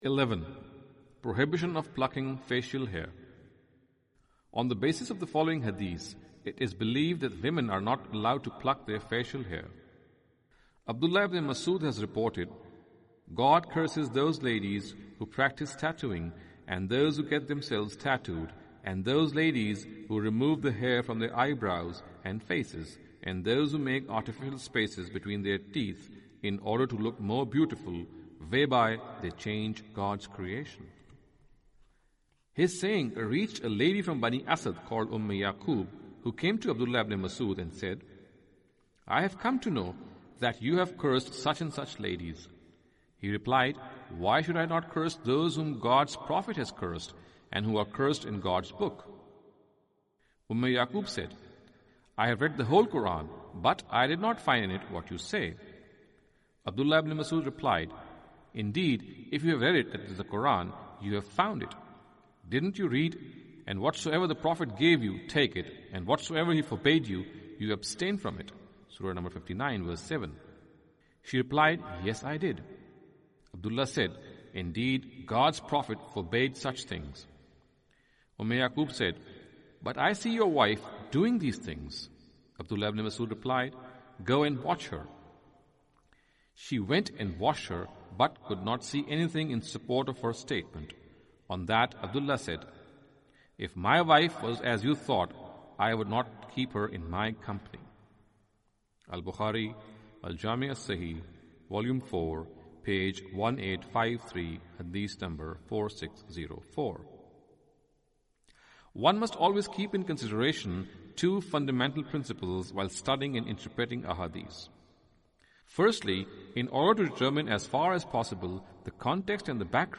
Audio book of English translation of Javed Ahmad Ghamidi's book "Islam and Women".